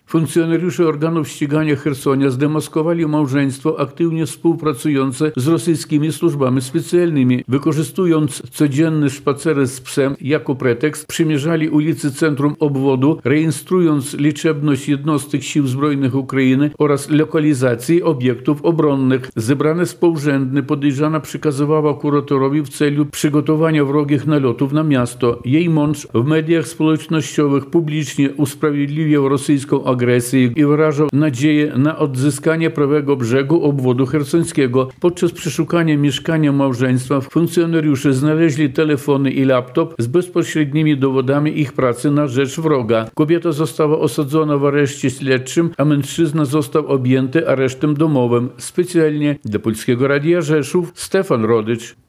Ze Lwowa dla Polskiego Radia Rzeszów